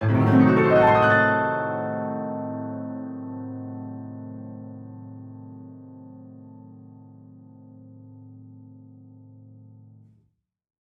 Index of /musicradar/gangster-sting-samples/Chord Hits/Piano
GS_PiChrdTrill-Adim.wav